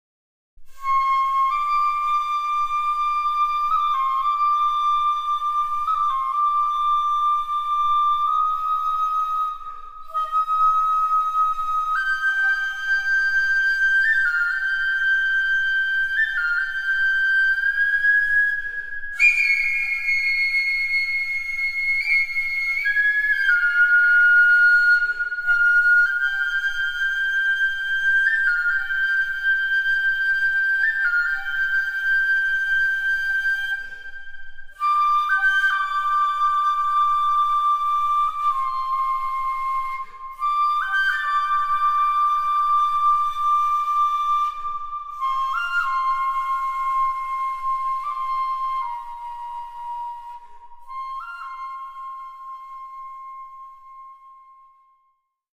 アルバム全体を流れる、エネルギッシュなグル－ブ感と、雄大なスケ－ルのサウンドをお楽しみください。
享受这从唱片中流淌出充满活力的精神，还有雄壮的音阶。